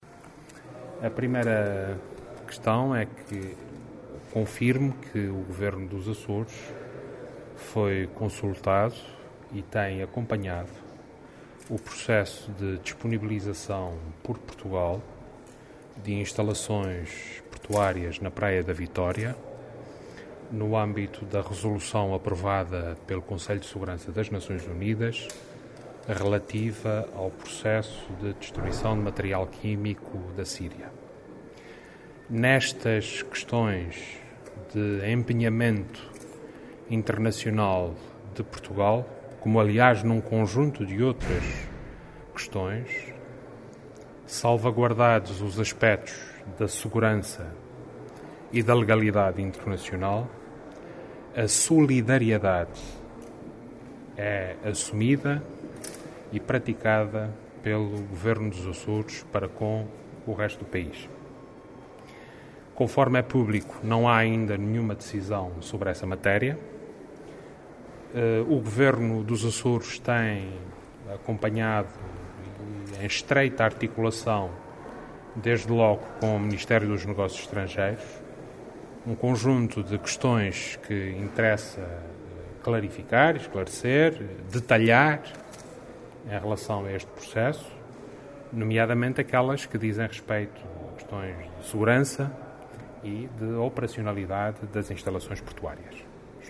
Declaração do Presidente do Governo dos Açores